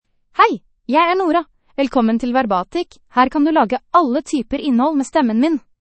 Nora — Female Norwegian Bokmål AI voice
Nora is a female AI voice for Norwegian Bokmål (Norway).
Voice sample
Listen to Nora's female Norwegian Bokmål voice.
Female